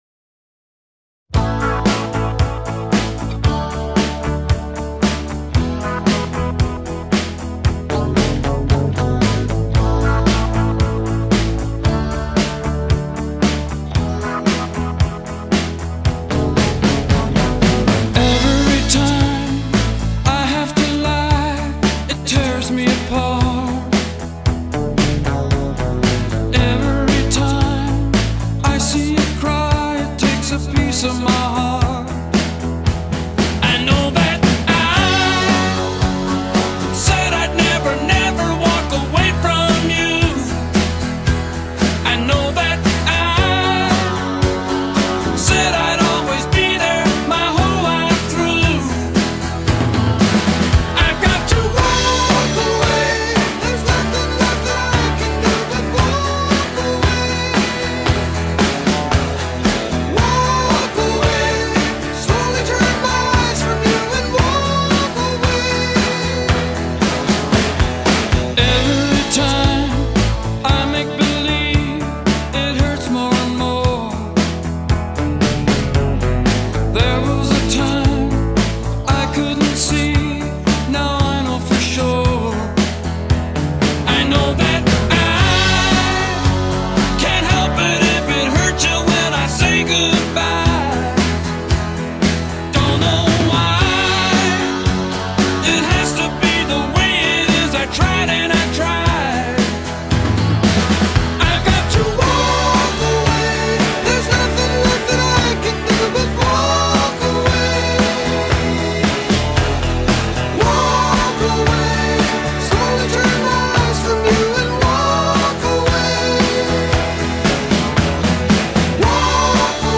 with its strong Travelling Wilburys vibe
soaring falsetto